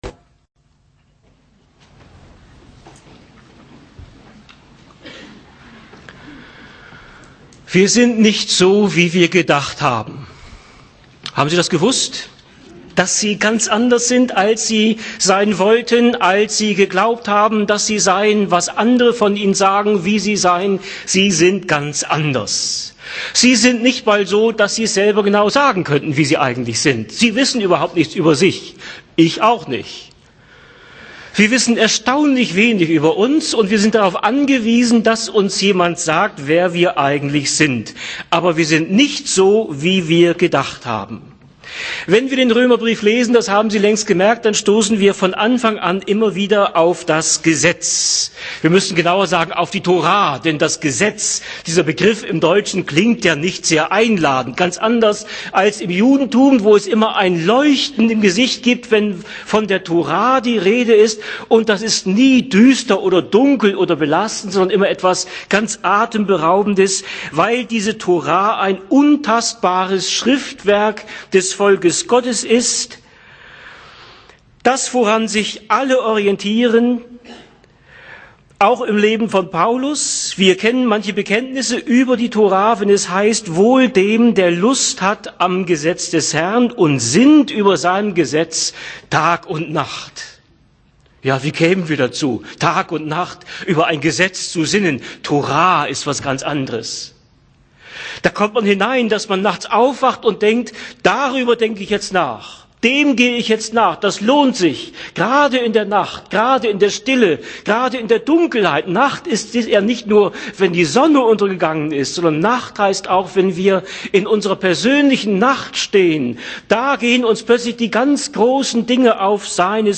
Passage: Römer 7,7-13 Dienstart: Predigt